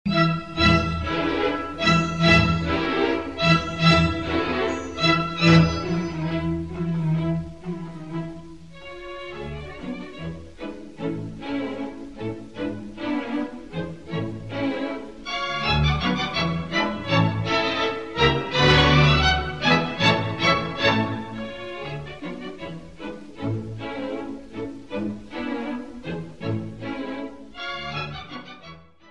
for orchestra (archive track